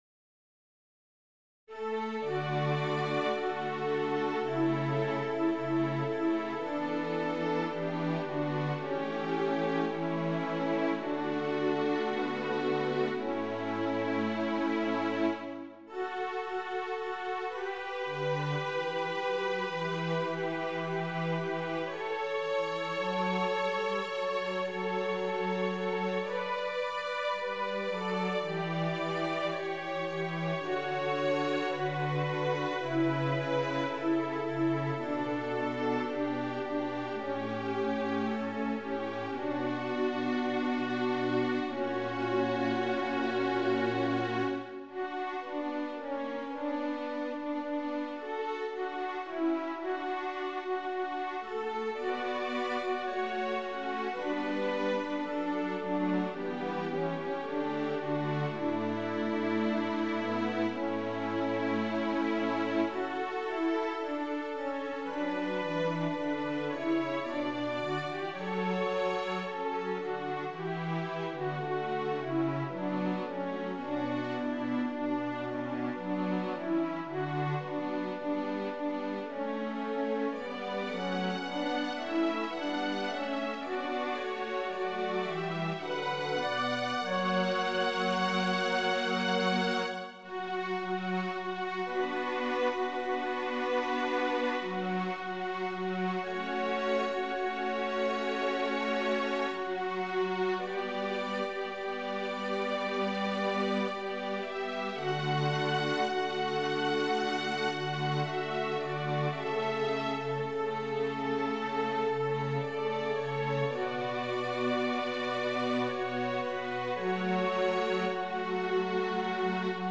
Number of voices: 4vv Voicing: SATB Genre: Sacred
Language: Latin Instruments: A cappella